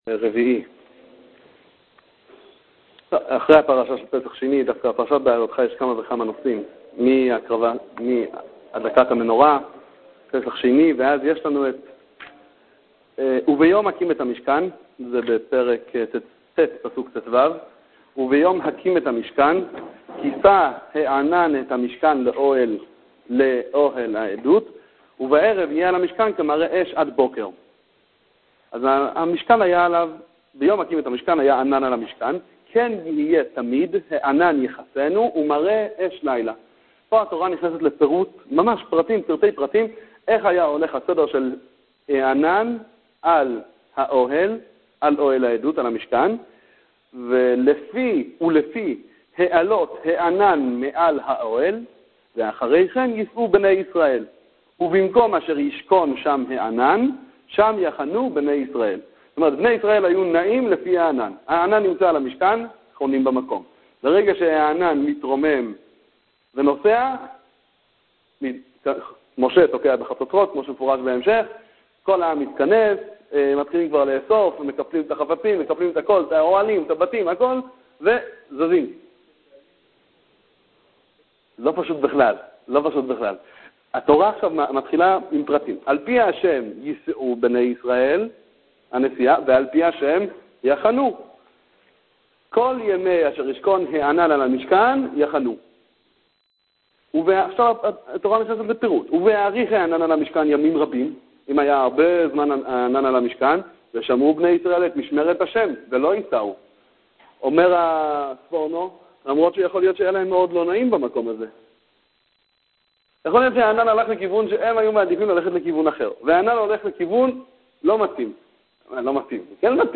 ביהמ"ד משאת מרדכי י"ז סיוון תשע"ב
שיעורי תורה